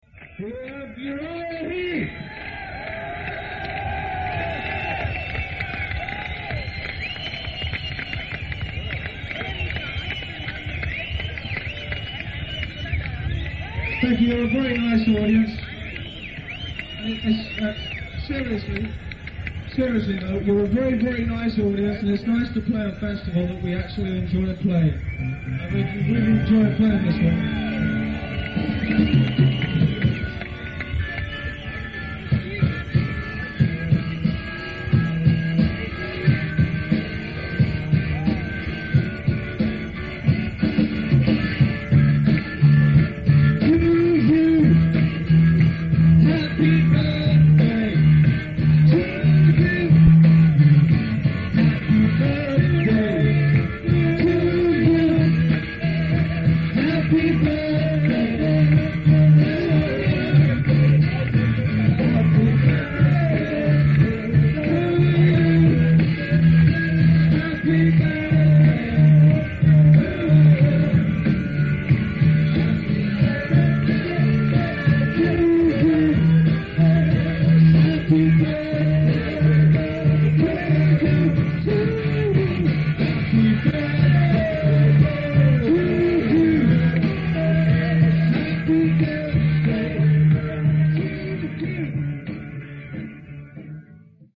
Venue:  Radstadion
Sound:  Remastered
Source:  Audience Recording